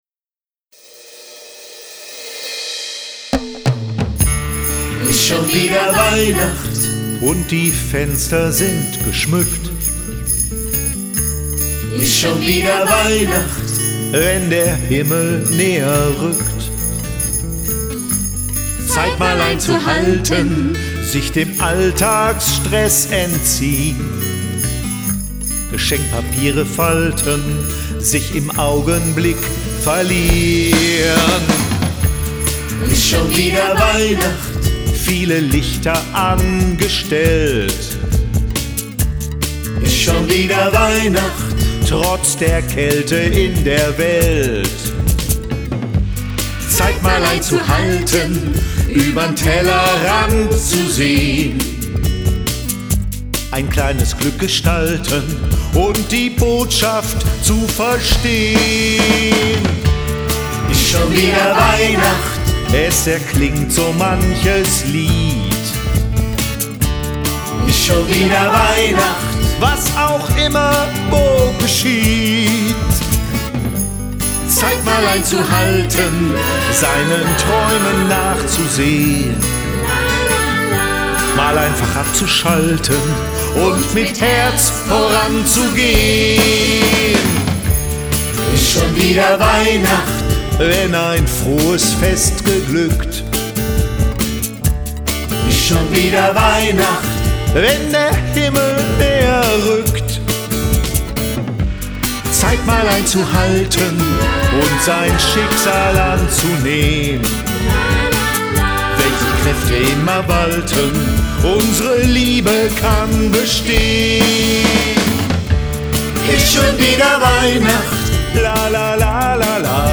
Alte und junge Borbecker sangen gemeinsam den neuen Weihnachtssong „Is schon wieder Weihnacht“.
So standen auf der Bühne ein altersgemischter Chor aus Eltern, Erziehenden und natürlich Kindern der Kita Heidbusch sowie der AWO Chor „Die Spätzünder“, eine Abordnung aus dem Ortsverein Schönebeck und einer Truppe der „Ruhrpott-Revue“ mit Weihnachtsmann.
hier den neuen Weihnachtssong von den AWO-Chören, „Ruhrpott-Revue“ und der Band „Instant Rock“ anhören.